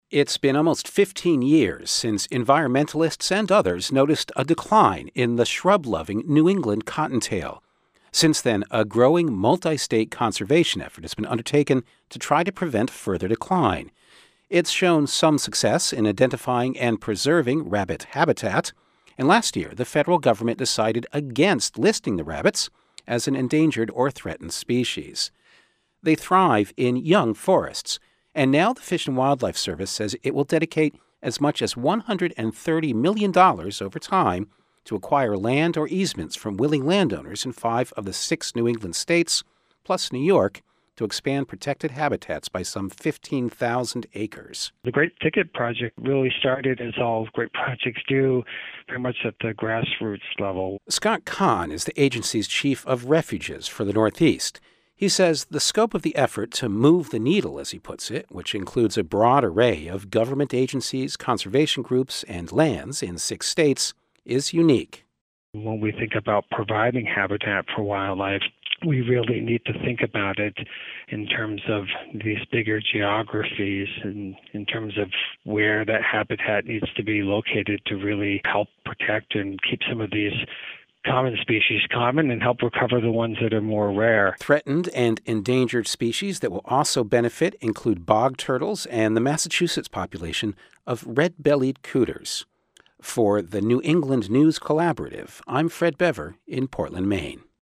This report comes from the New England News Collaborative.